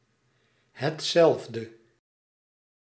Ääntäminen
UK : IPA : /əˈlaɪk/ US : IPA : [əˈlaɪk]